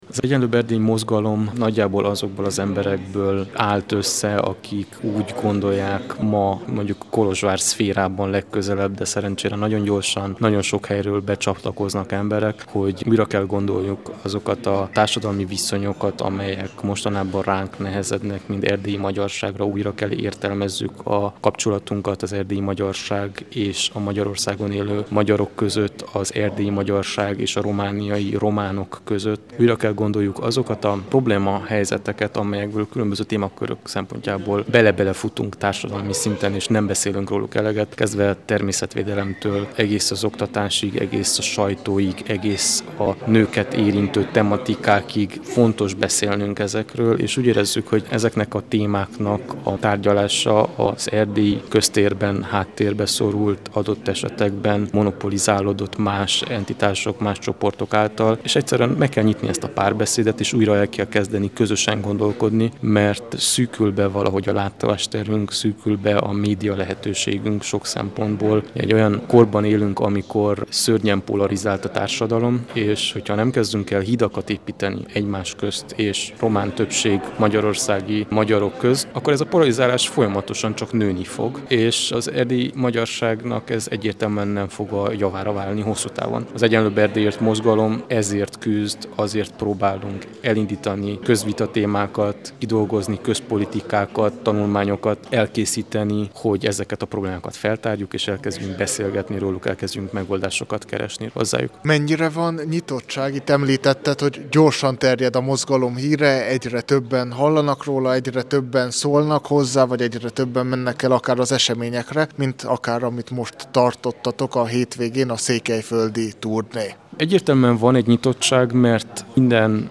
A csíkszeredai esemény után beszélgettünk az előadókkal.